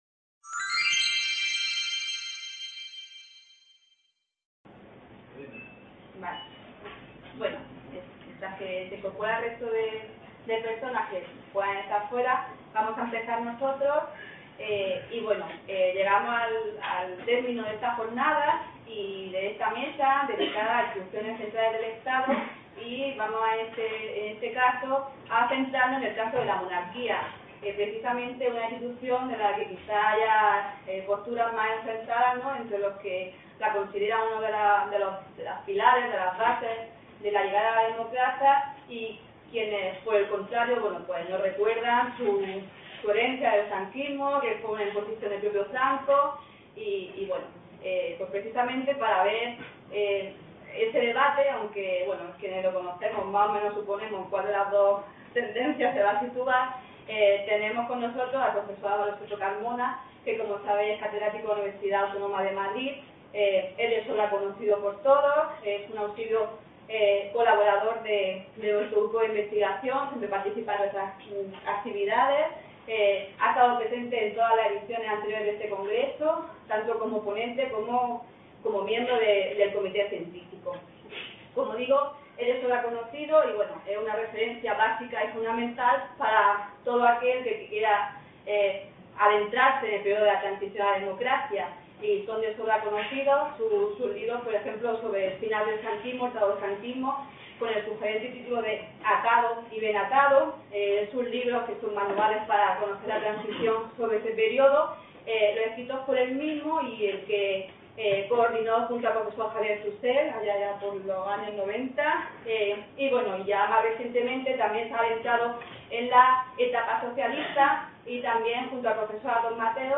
C.A. Almeria - VI Congreso Internacional Historia de la Transición en España, Las instituciones.